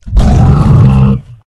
boar_aggressive_1.ogg